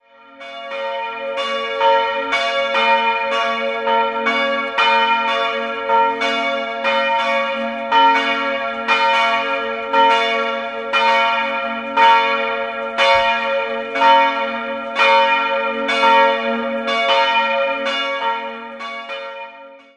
Sie wurde in den Jahren 1957/58 erbaut, zehn Jahre später konnte eine Orgel angeschafft werden. 2-stimmiges Kleine-Terz-Geläute: b'-des'' Die große Glocke wiegt 365 kg, die kleinere 225 kg. Beide Glocken stammen aus dem Jahr 1958 und wurden von der Firma Bachert in Karlsruhe gegossen.